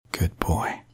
Man Saying Good Boy Botón de Sonido